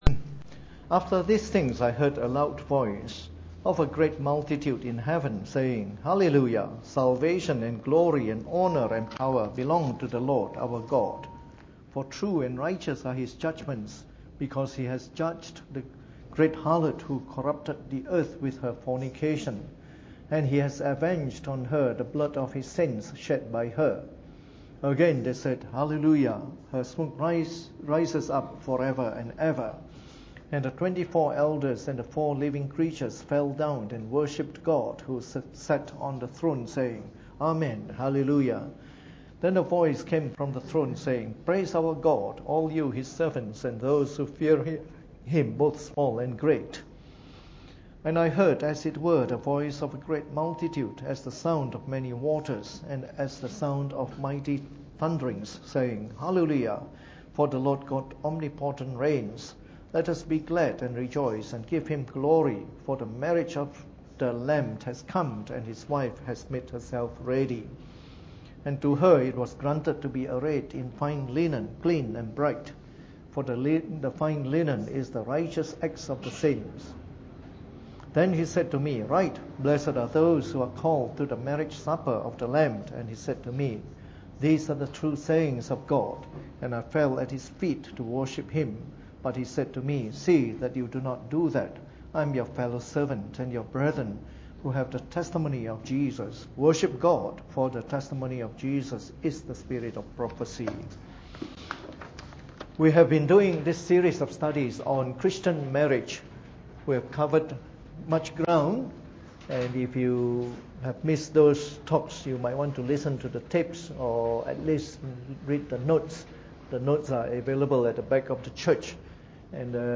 Preached on the 5th of August 2015 during the Bible Study, from our series on “Christian Marriage.”